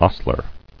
[os·tler]